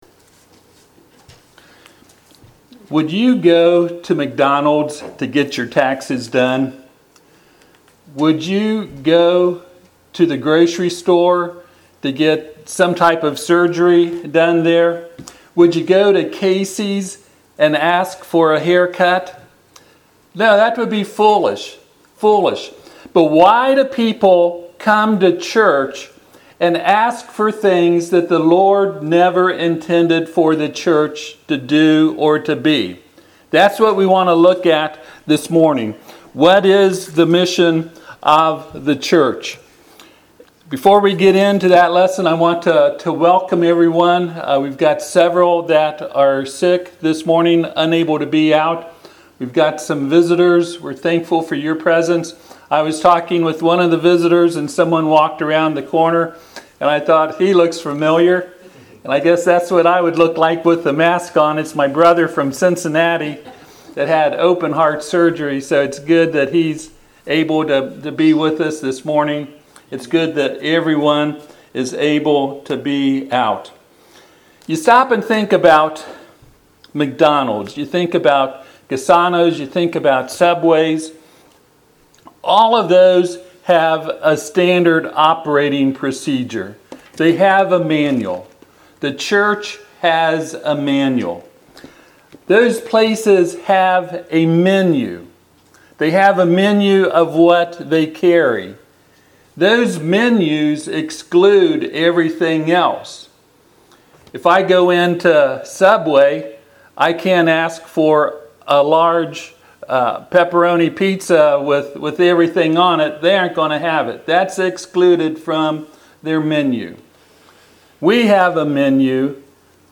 Passage: Ephesians 3:10-11 Service Type: Sunday AM « Amos.